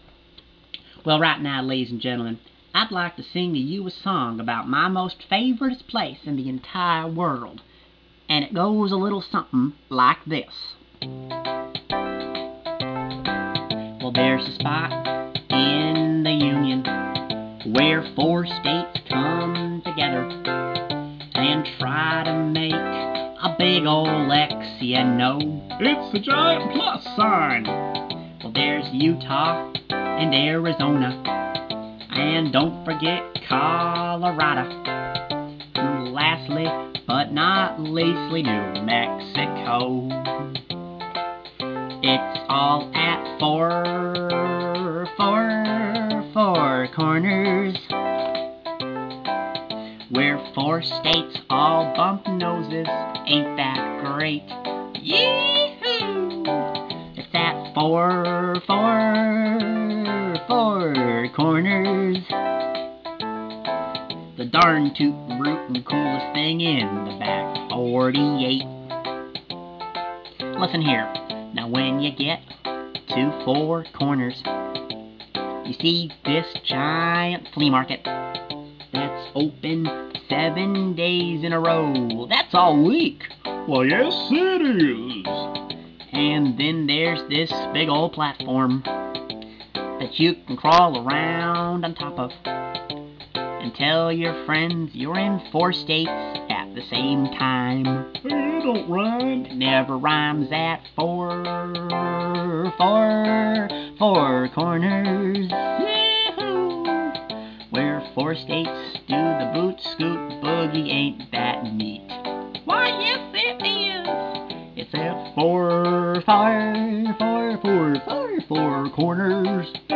(Yes, the hokey accent is intentional, no, I don't normally sound like that.)